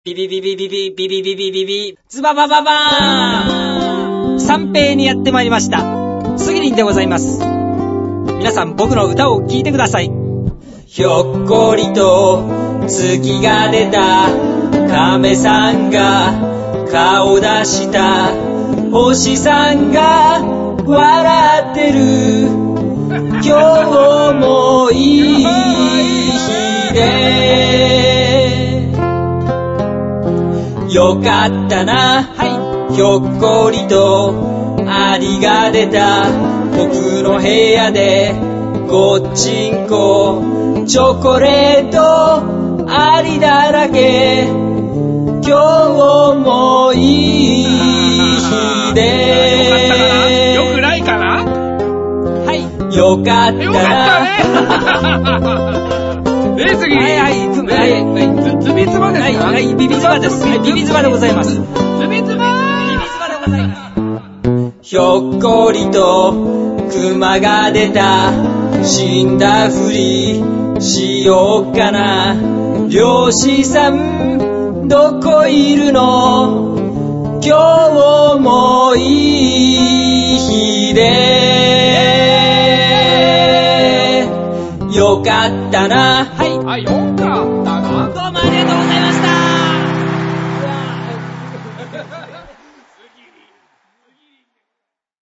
僕が小学校時代に夏休みの宿題としてつくった音頭という名のロックンロール。
ひょっこり音頭(モノラル：376KByt)